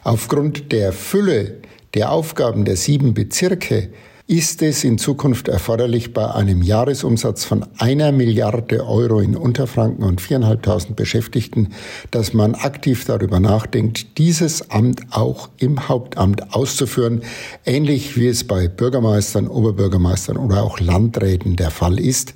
Wir haben Stefan Funk gefragt, was er selbst darüber denkt: Stefan Funk, der unterfränkische […]
Stefan Funk, der unterfränkische Bezirkstagspräsident.